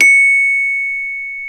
CELESTE 2 0D.wav